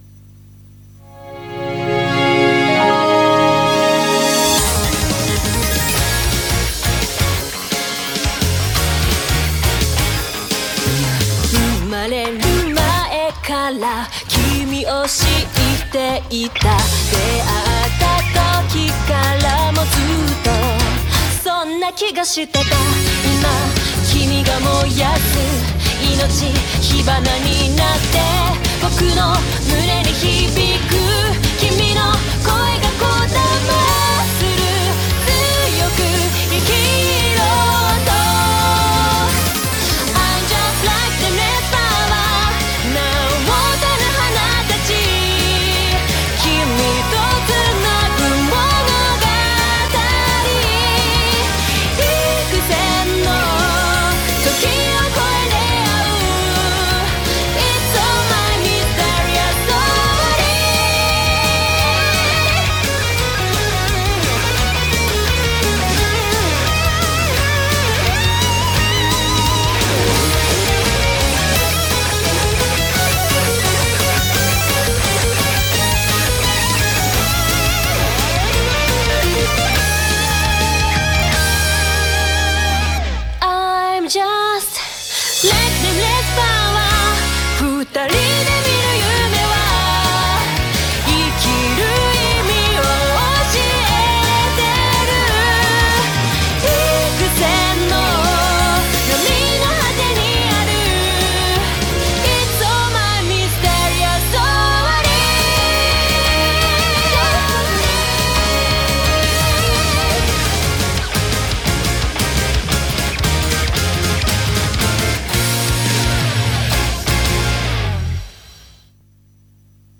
BPM172
MP3 QualityLine Out